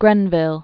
(grĕnvĭl, -vəl), George 1712-1770.